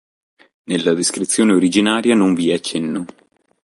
Uitgesproken als (IPA)
/ˈvi/